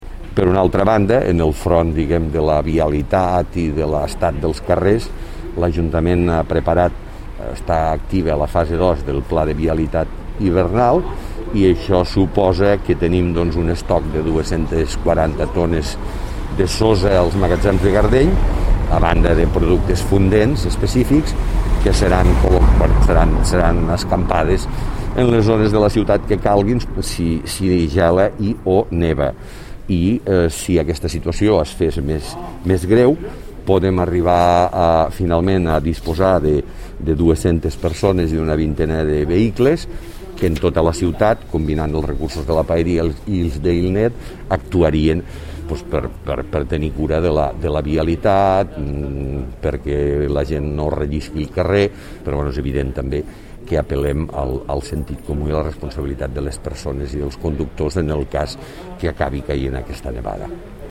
Tall de veu alcalde de Lleida sobre les actuacions de vialitat previstes en cas de nevades.
tall-de-veu-alcalde-de-lleida-sobre-les-actuacions-de-vialitat-previstes-en-cas-de-nevades